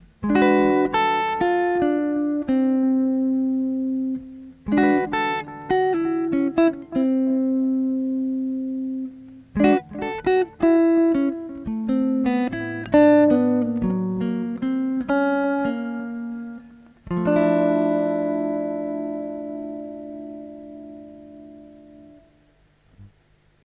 Solo-Gitarre